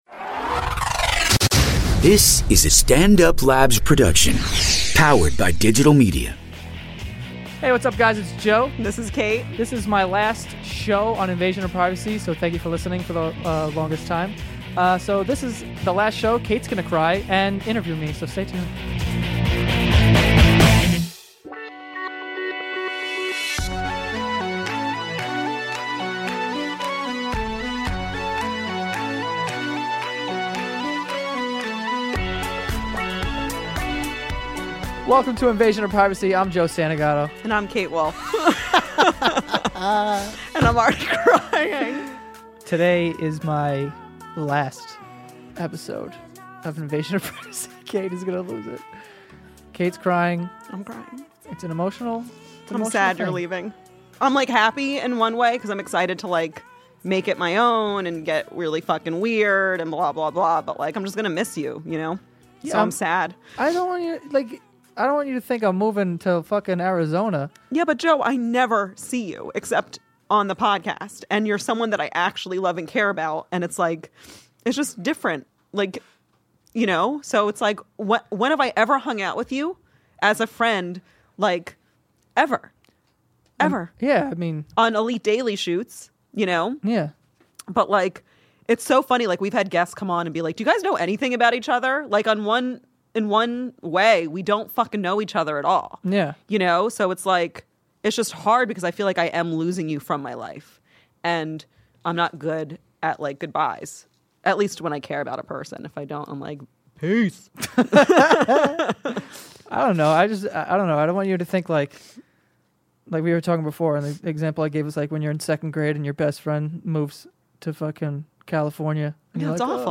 They also talk about what the last couple of years have been like co-hosting together, and how much they've both grown. They say goodbye to each other, and only one of them cries